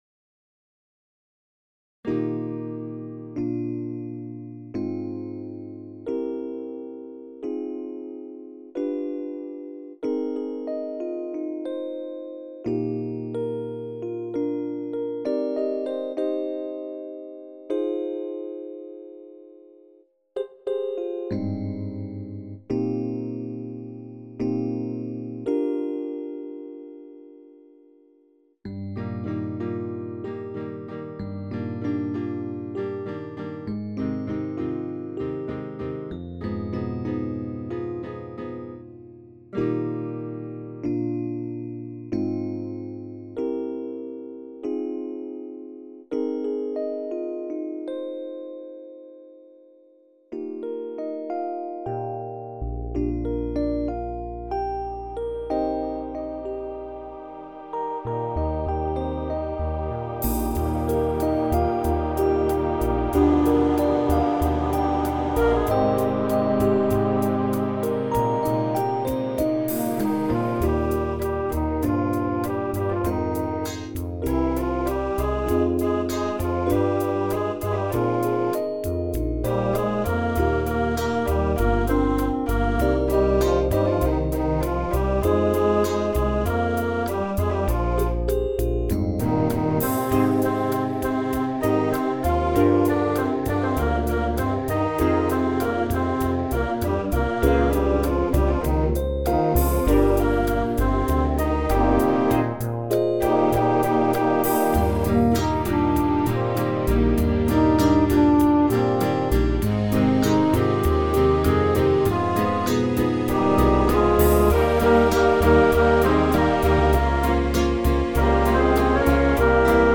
WAVE mixed with VST-effects.